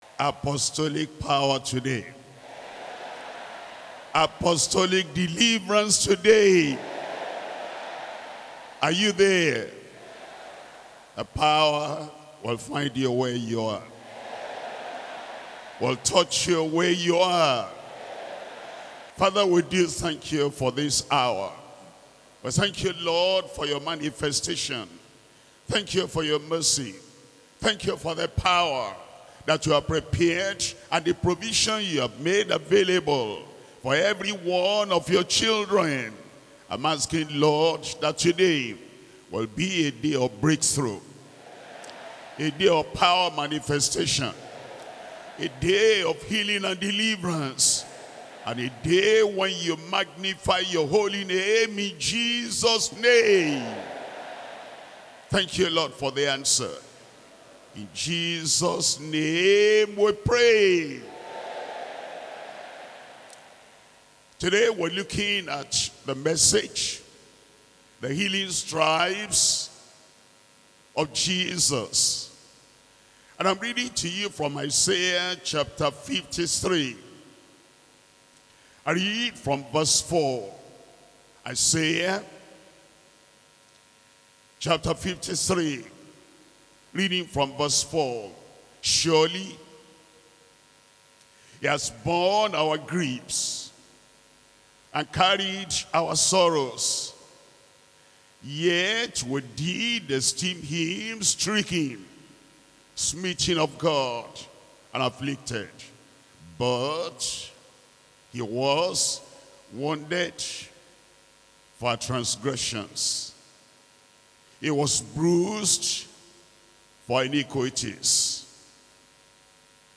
Sermons - Deeper Christian Life Ministry
2025 Global December Retreat